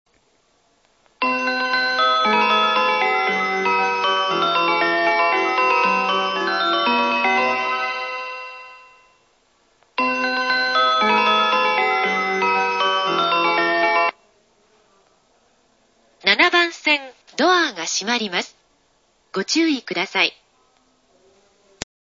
◆発車放送
我孫子駅１番線